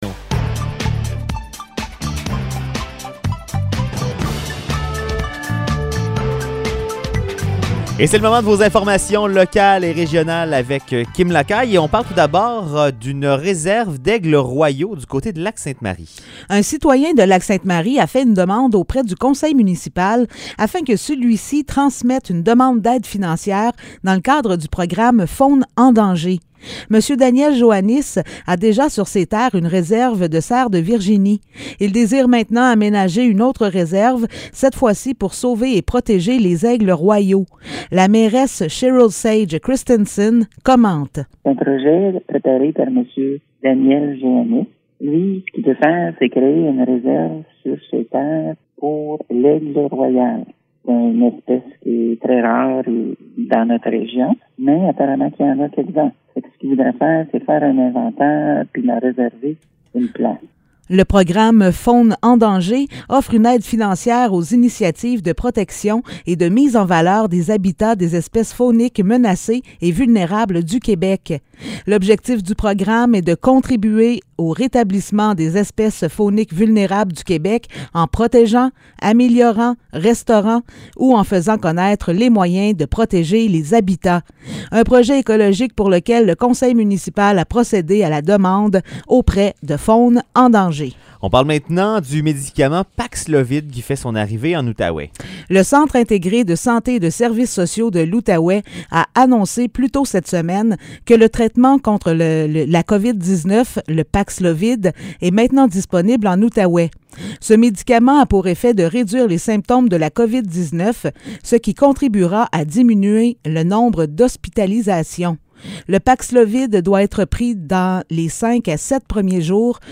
Nouvelles locales - 18 mars 2022 - 16 h